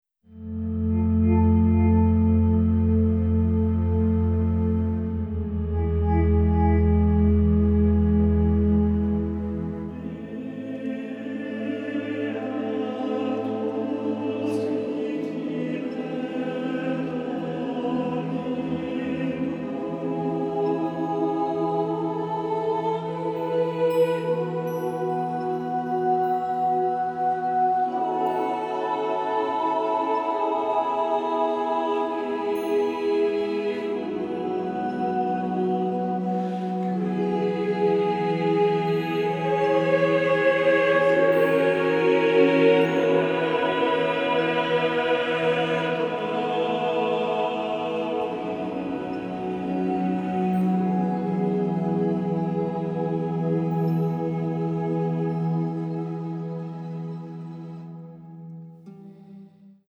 orchestra and chorus